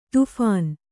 ♪ tuphān